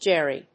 音節Ger・ry 発音記号・読み方
/géri(米国英語), ˈdʒeri:(英国英語)/